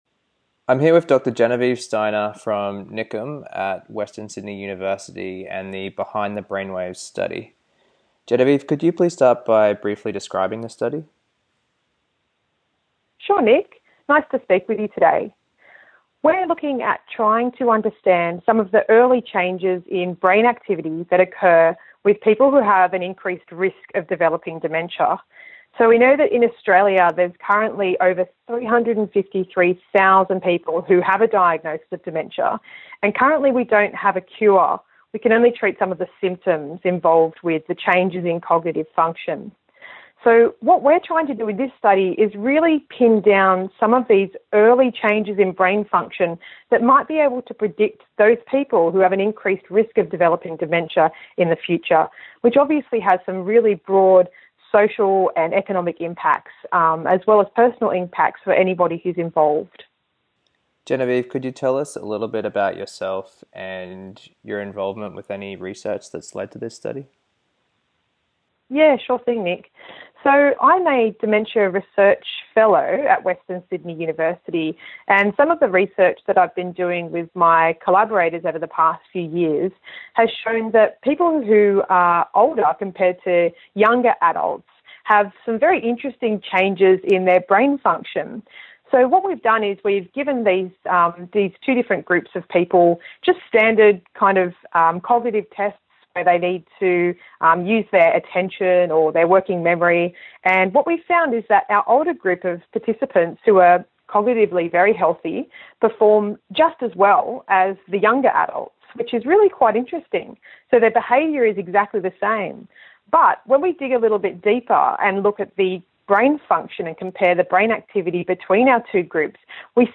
Researcher Interview